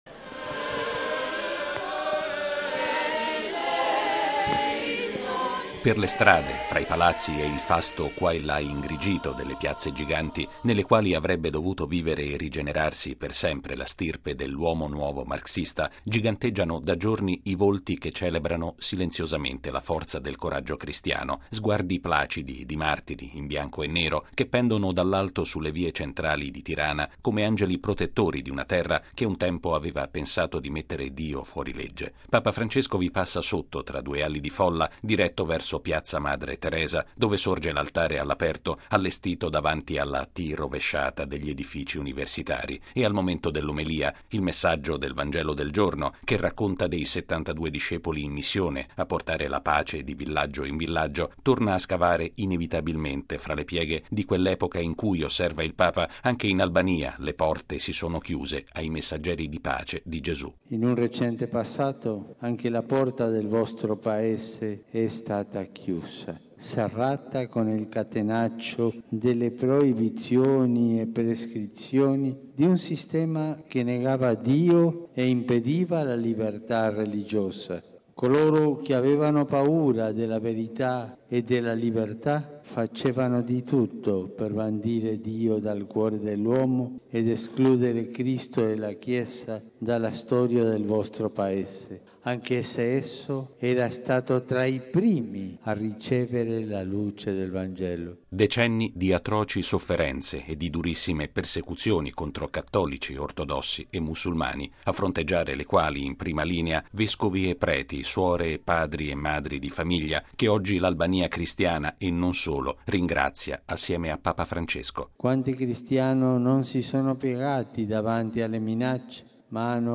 È il messaggio centrale che Papa Francesco ha rivolto alle decine di migliaia di persone che hanno partecipato alla Messa celebrata a Tirana e all’Angelus che ha concluso la mattina di impegni del suo quarto viaggio apostolico. Il servizio